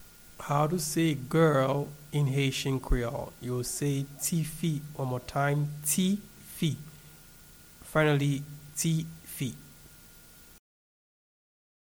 Pronunciation and Transcript:
Girl-in-Haitian-Creole-Ti-fi-pronunciation.mp3